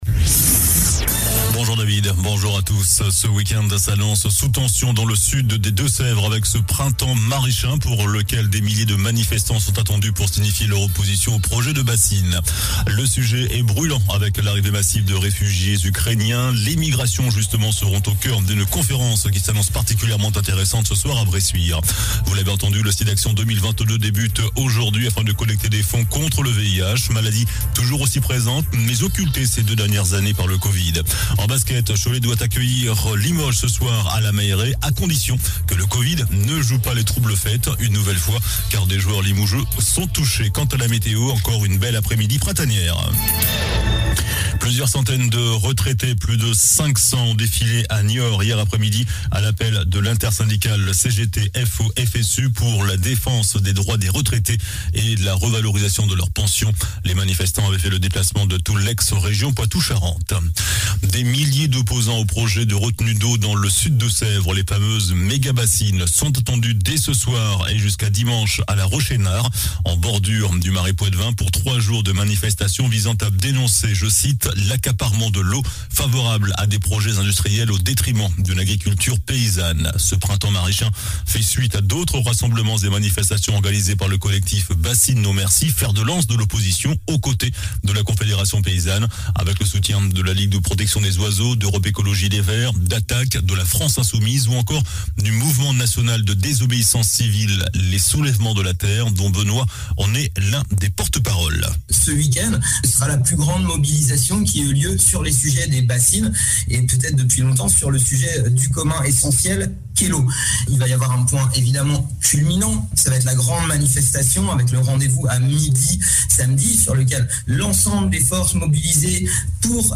JOURNAL DU VENDREDI 25 MARS ( MIDI )